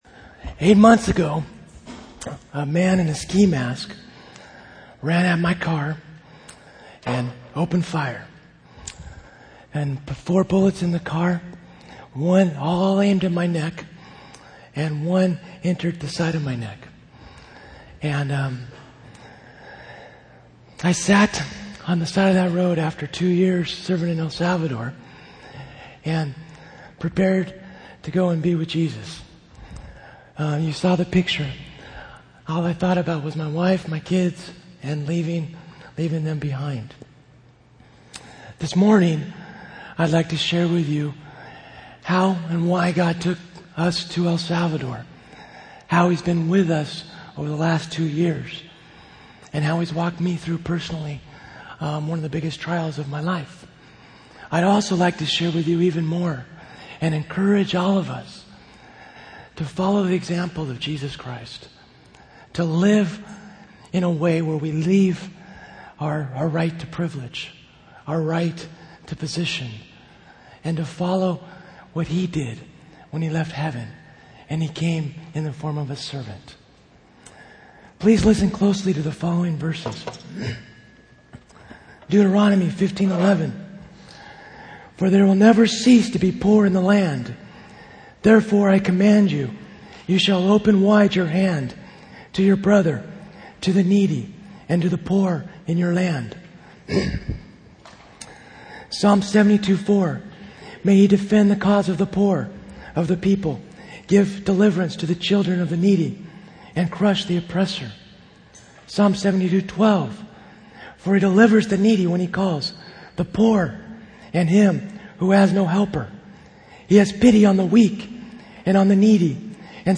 One of the places I spoke was at the Master's College in Santa Clarita, CA. If you would like to download the message you can save the following file and listen: Master's College Message Sept. 19th .